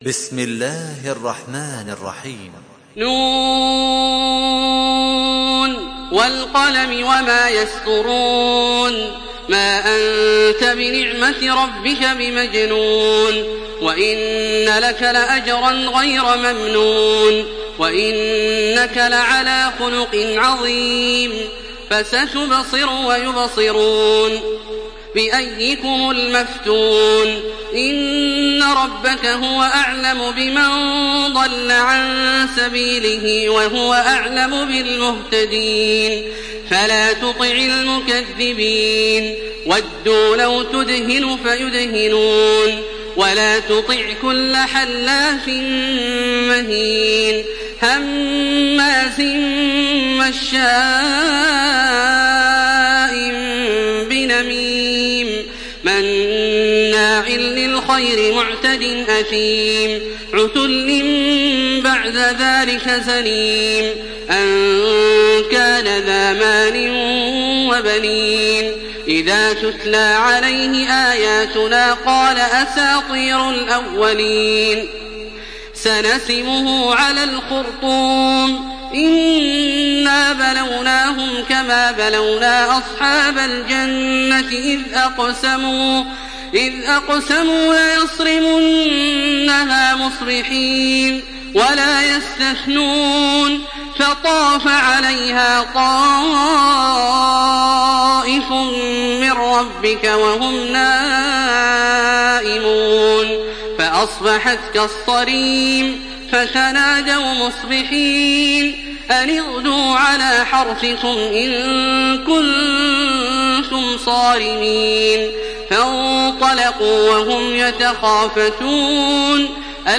تحميل سورة القلم بصوت تراويح الحرم المكي 1427
مرتل